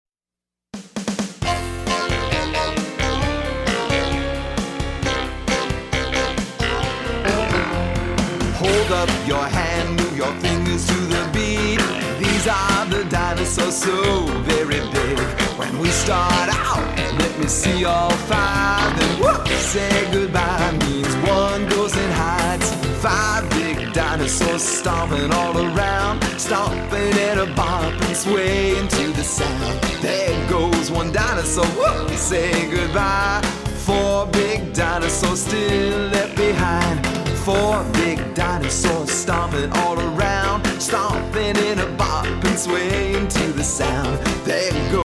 a children’s chorus